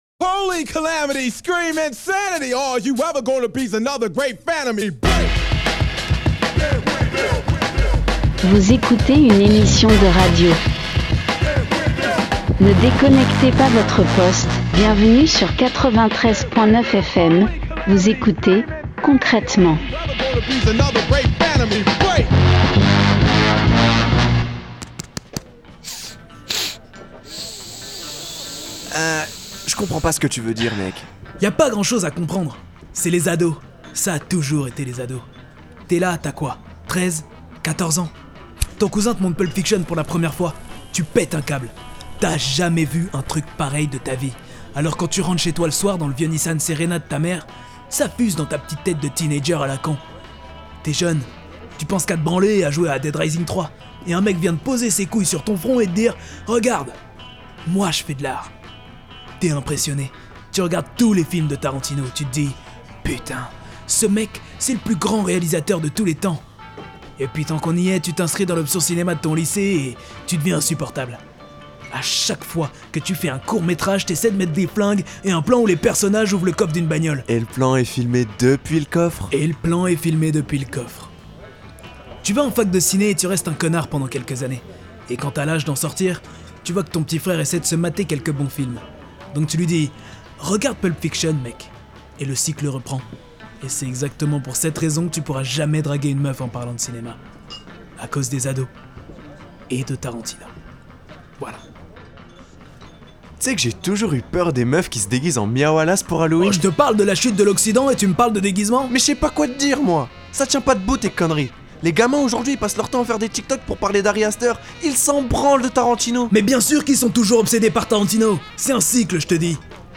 Partager Type Création sonore Société mercredi 24 mai 2023 Lire Pause Télécharger Qui dit season finale dit nostalgie.